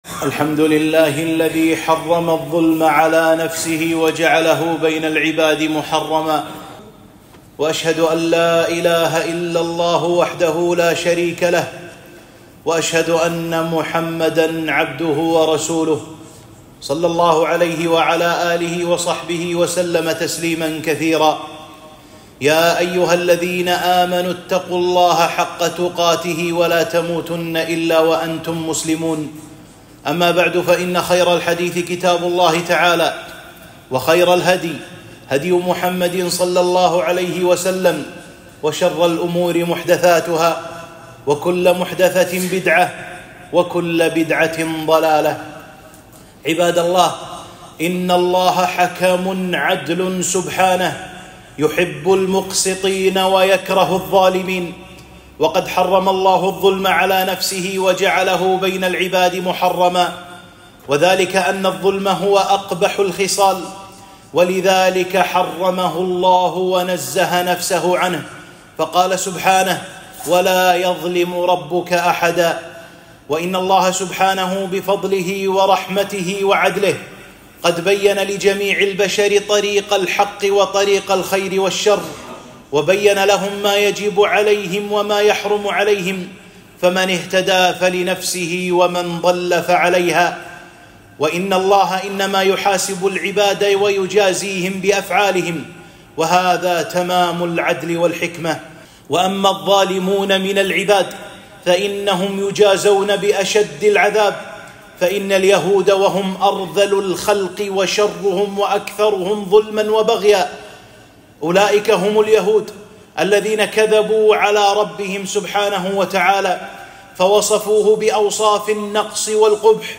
خطبة - الظلم ظلمات يوم القيامة، واتق دعوة المظلوم - دروس الكويت